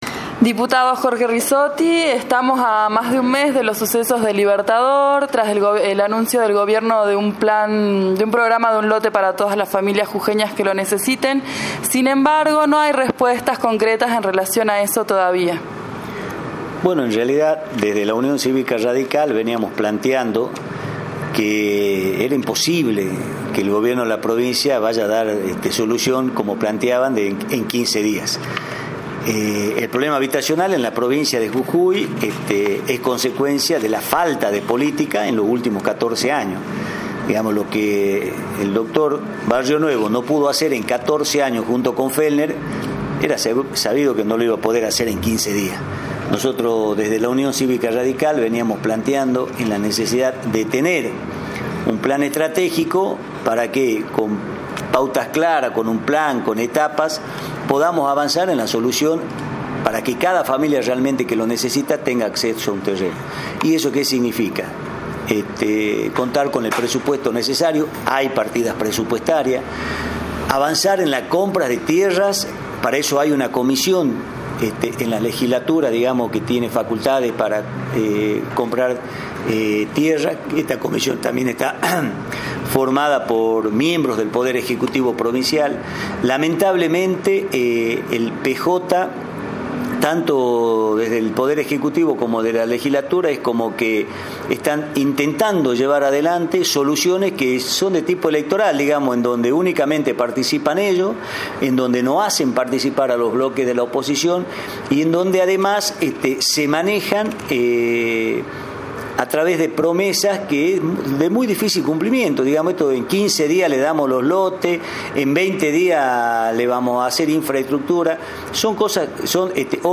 Adjuntamos en archivo adjunto, y con formato mp3 (audio), declaraciones del diputado y actual candidato a renovar su banca Jorge 'Colo' Rizzotti.